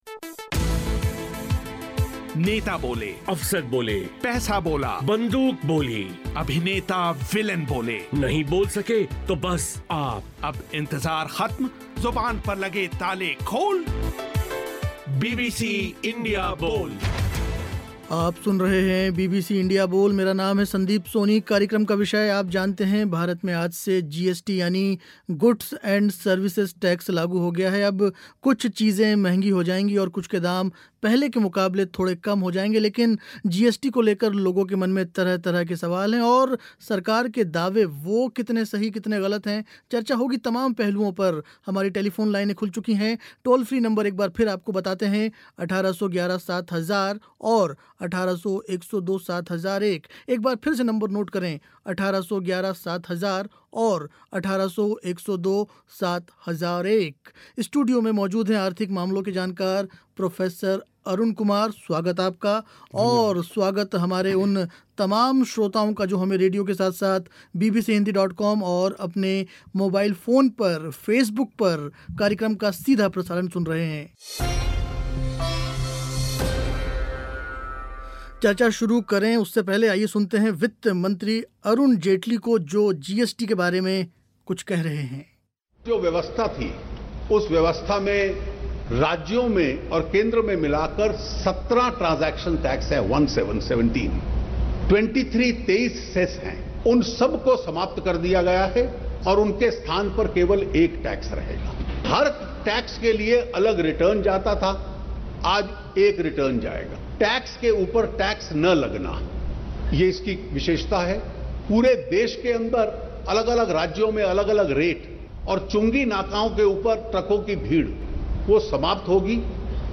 इँडिया बोल में आज चर्चा हुई जीएसटी के विभिन्न पहलुओं पर. स्टूडियो में मौजूद थे आर्थिक मामलों के जानकार